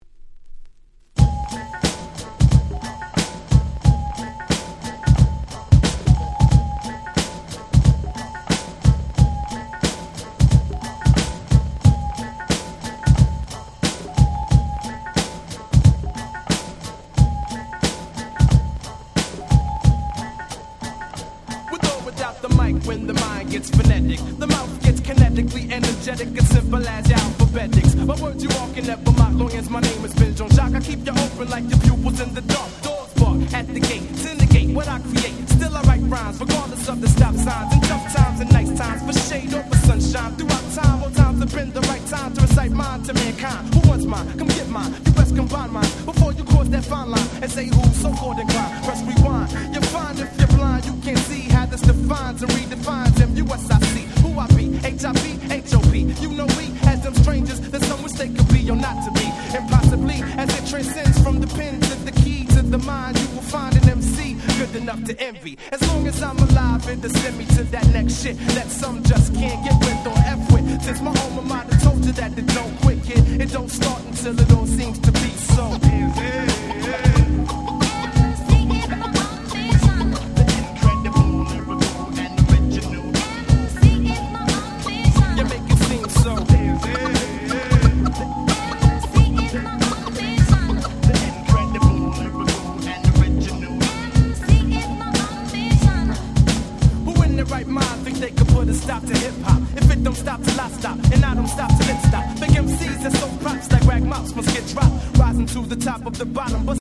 95' Smash Hit Underground Hip Hop !!